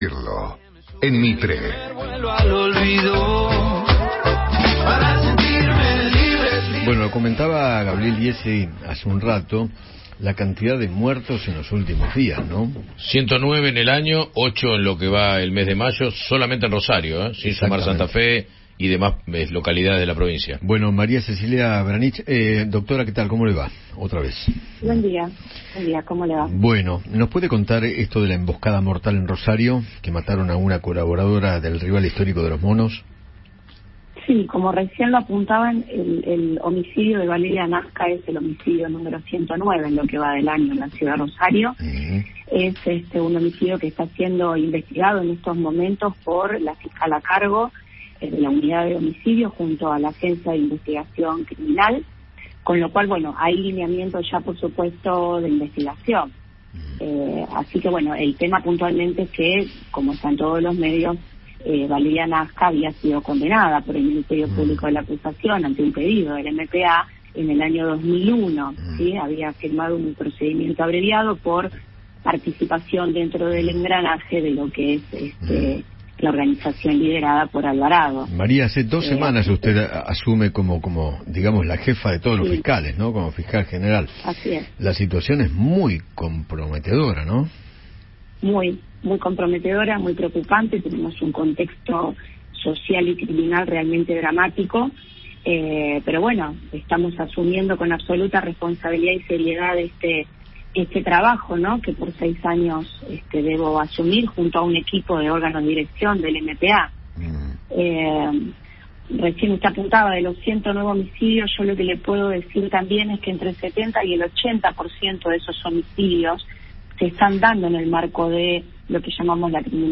María Cecilia Vranicich, fiscal general de Santa Fe, dialogó con Eduardo Feinmann